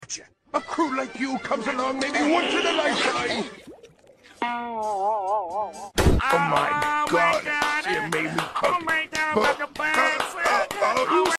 Follow for more deep fried FreakBob sound effects free download